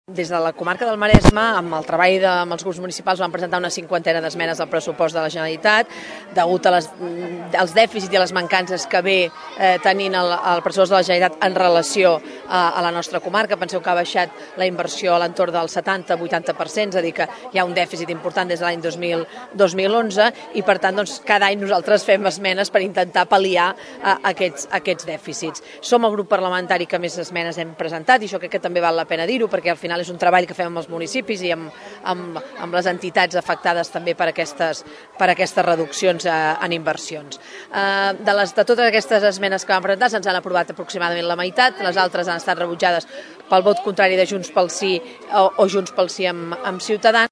Des del Maresme, els socialistes van presentar tot un seguit d’esmenes en l’àmbit de l’educació, la salut, les infraestructures o el medi ambient, degut al dèficit de la proposta del Govern de Junts pel Sí. Ho explica la diputada Socialista, Alícia Romero, en declaracions a Ràdio Tordera.